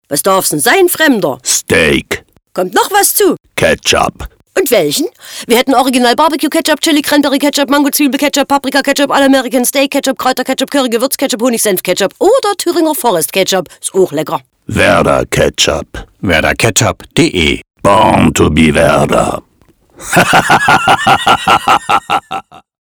Vom Aufsager zum Kopfkino
01-Nur-Sprache.mp3